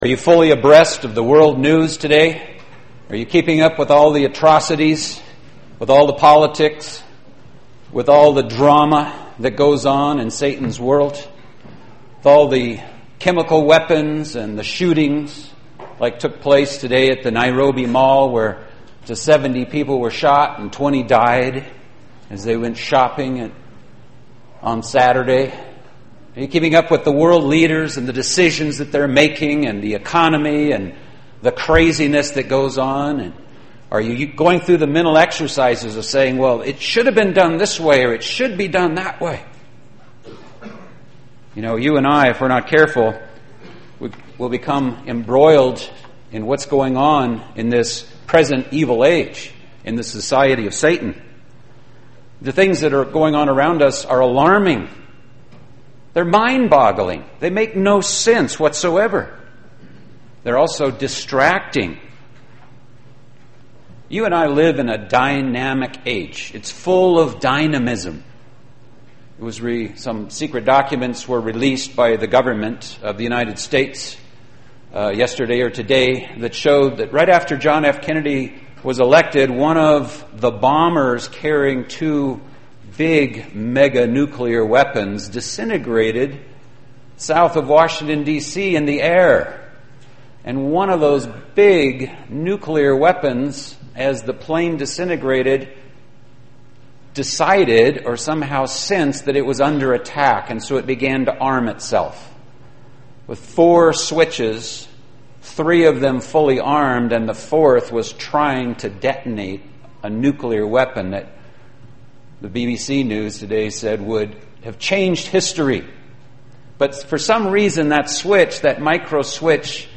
This sermon was given at the Canmore, Alberta 2013 Feast site.